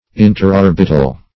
Meaning of interorbital. interorbital synonyms, pronunciation, spelling and more from Free Dictionary.